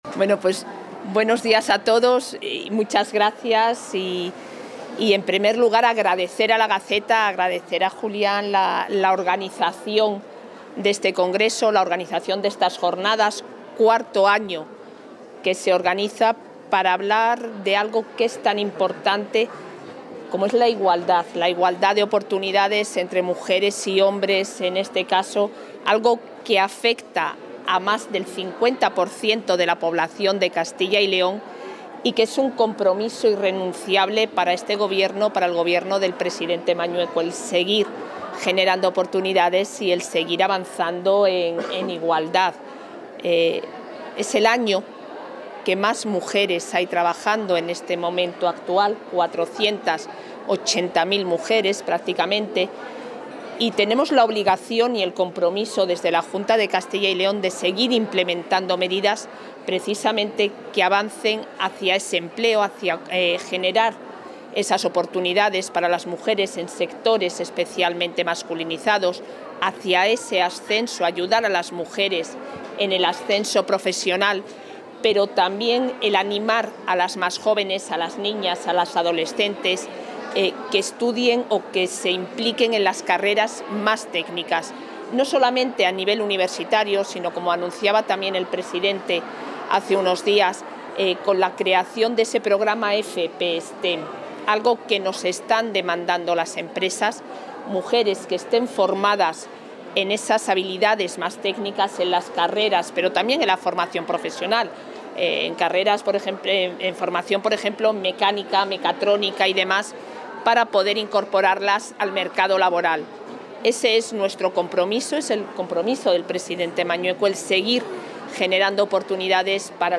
Declaraciones de la vicepresidenta de la Junta.